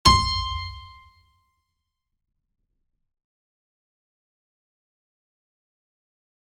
piano-sounds-dev
c5.mp3